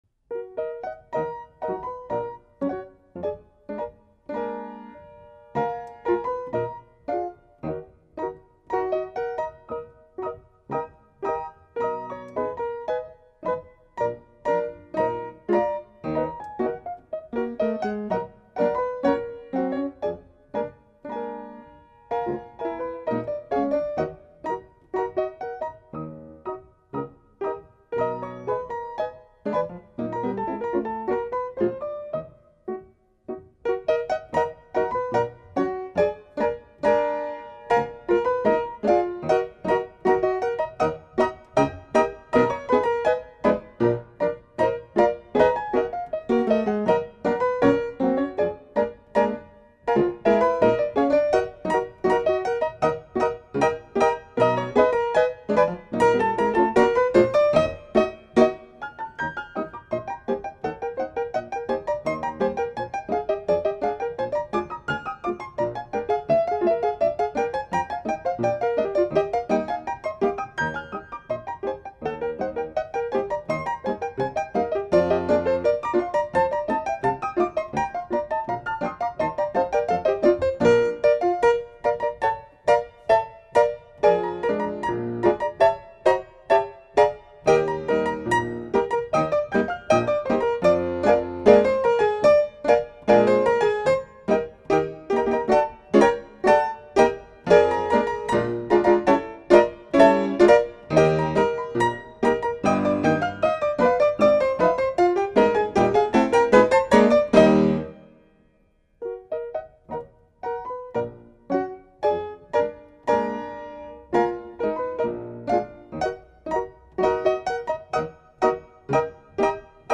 POLKA TRACK.mp3